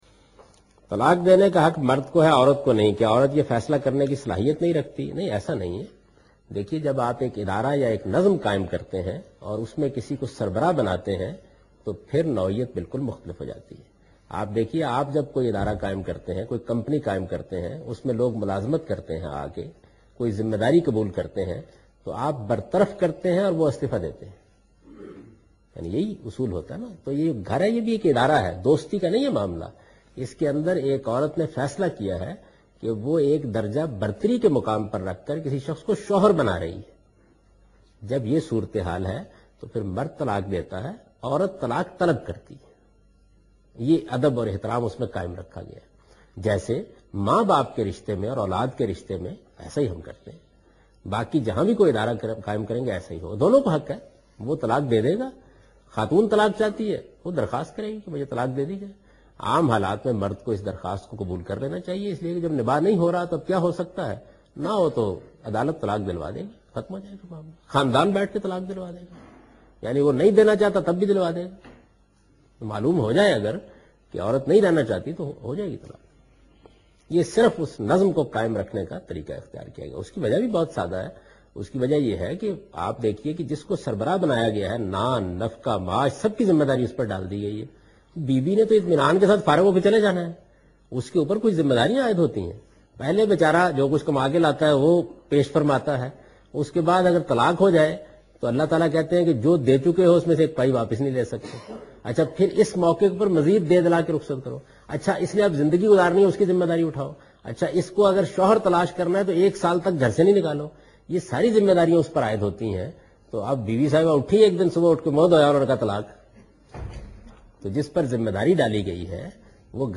Category: Reflections / Questions_Answers /
Javed Ahmed Ghamidi answers a question why a woman does not have right to give divorce in Islam.